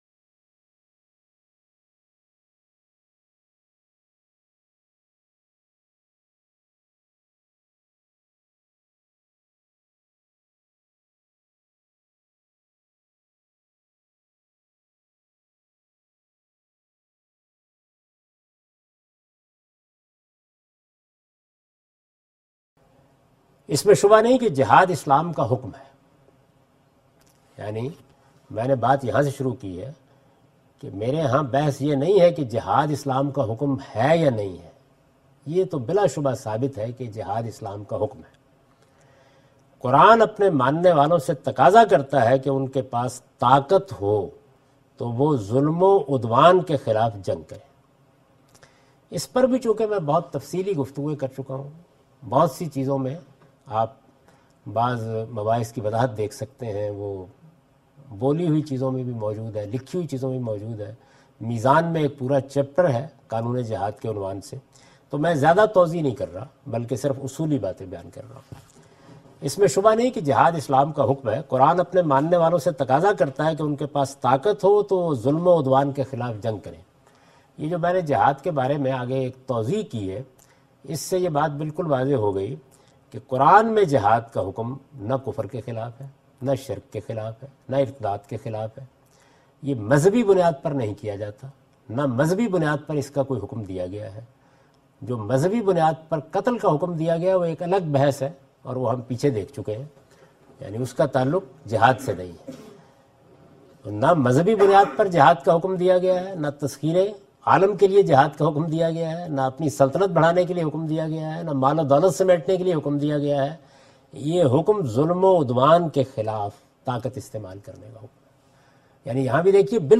In this video, Javed Ahmad Ghamidi presents the "Counter Narrative" of Islam and discusses the "Jihad and Ethics". This lecture was recorded on 19th January 2016 in Kuala Lumpur (Malaysia)